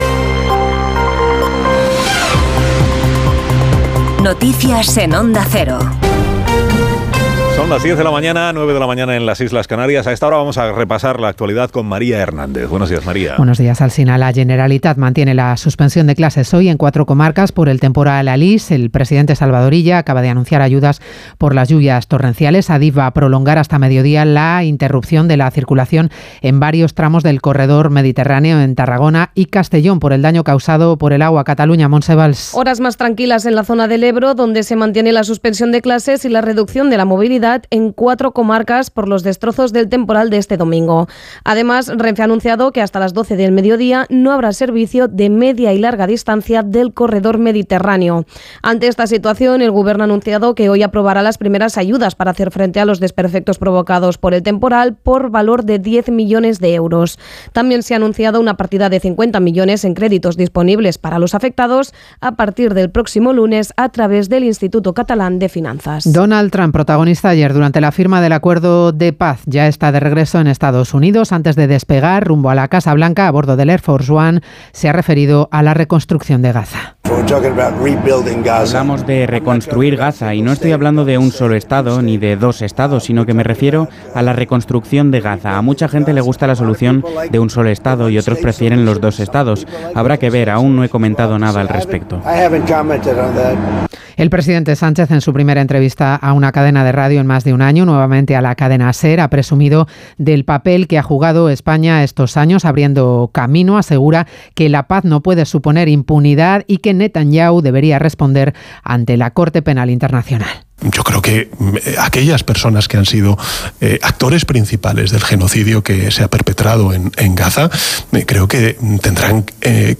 Infórmate de todas las noticias y actualidad informativa en los boletines informativos de Onda Cero: Noticias de última hora, noticias de hoy en España y el mundo